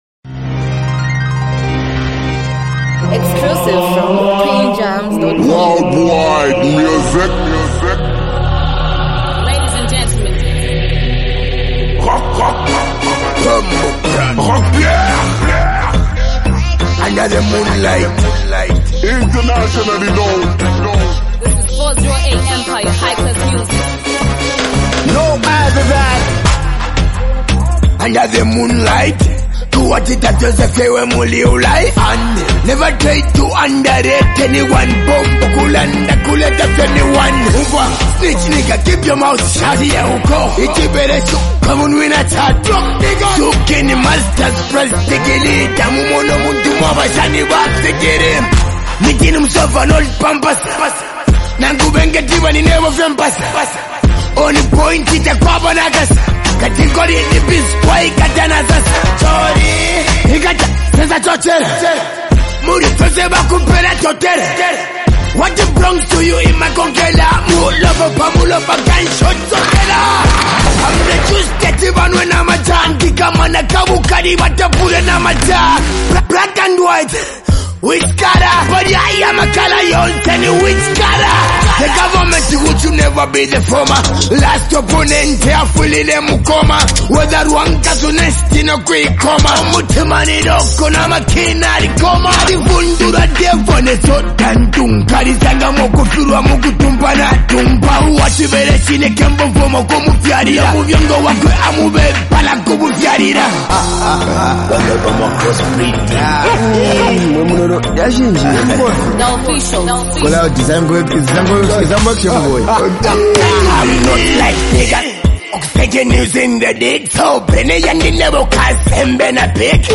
smooth and emotional song
heartfelt love song